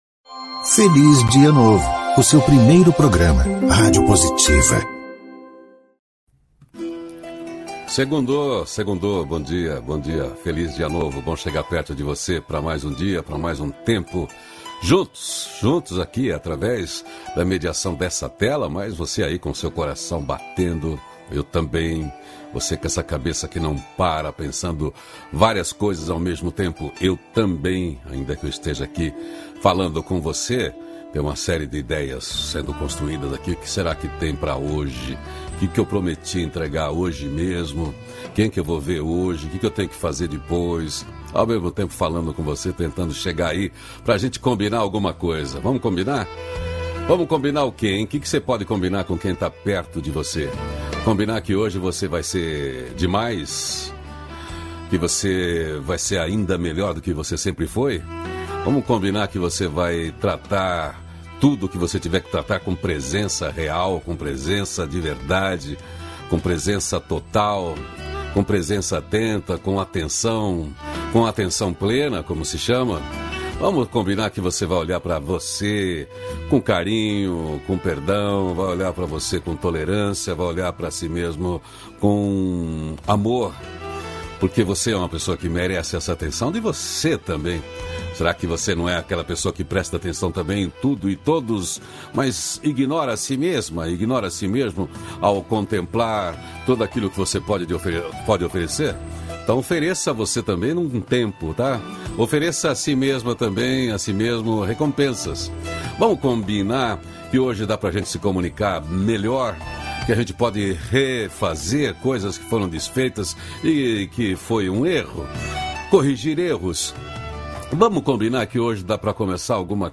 Hoje é dia de Insight com a narração de um bom argumento do livro
ao vivo